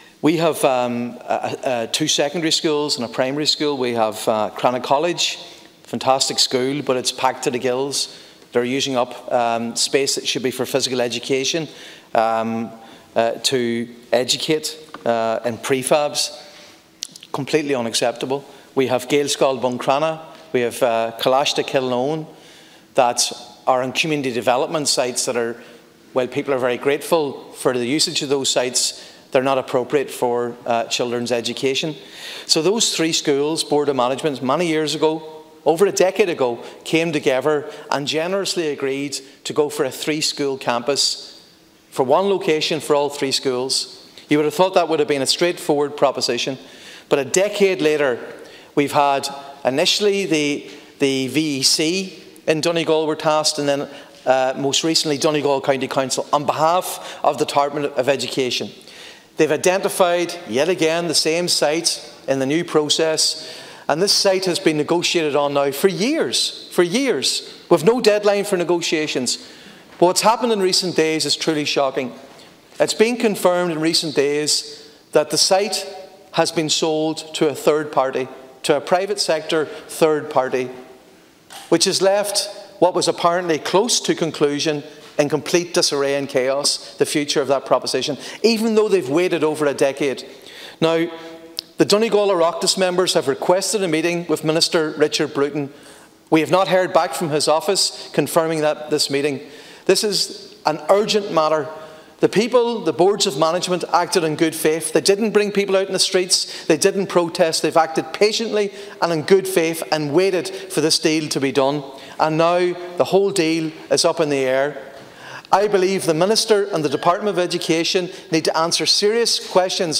Speaking in the Seanad this afternoon, Donegal Senator Padraig MacLochlainn says the community of Buncrana deserve clarity on the issue and reassurance from Government that it will find a resolution: